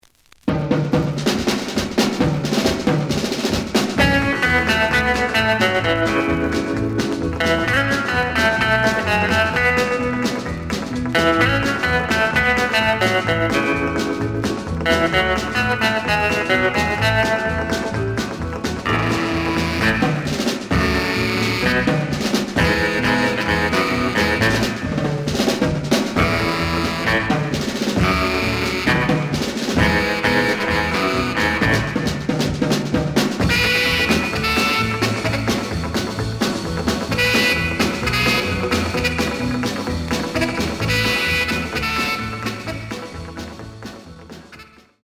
The audio sample is recorded from the actual item.
●Genre: Rhythm And Blues / Rock 'n' Roll
Some click noise on A side due to scratches.